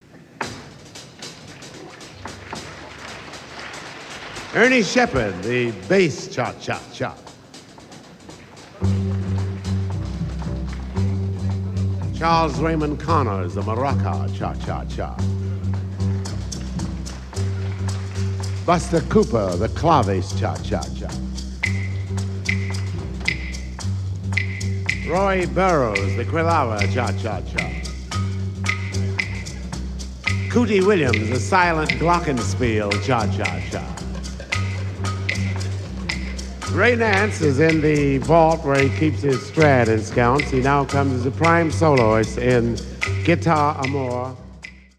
Helsinki 1963